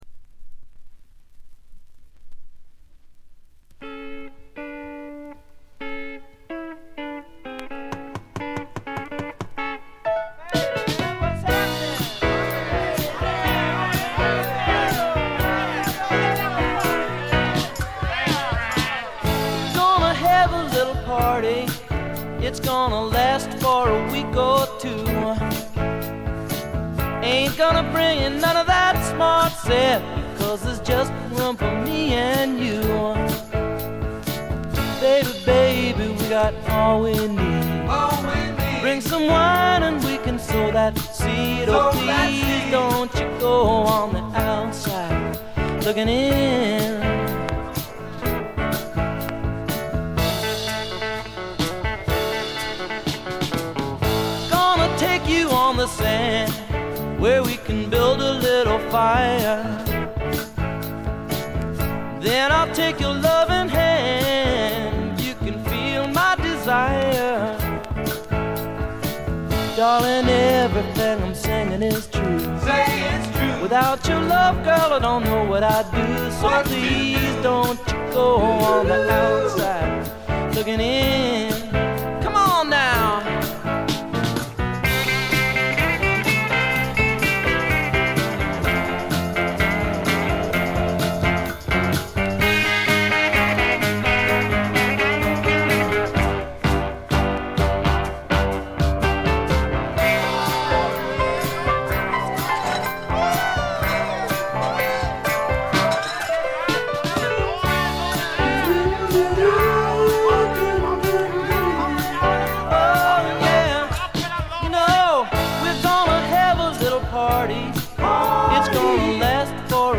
ところどころで軽微なチリプチ。
A1序盤のプツ音2回、B2冒頭のプツ音1回あたりが気になった程度です。
試聴曲は現品からの取り込み音源です。